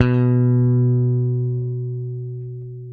-JP ROCK B.3.wav